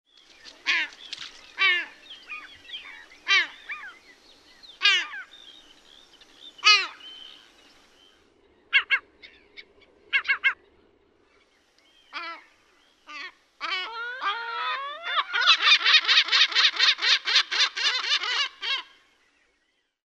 Selkälokki
Larus fuscus
Ääni: Matalaa ja nopeaa kaklatusta.